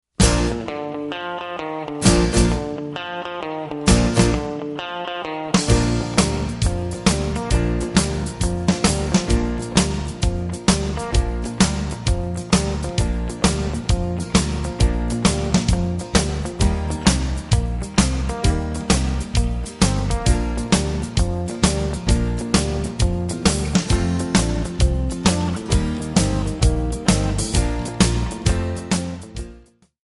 Backing track files: 1990s (2737)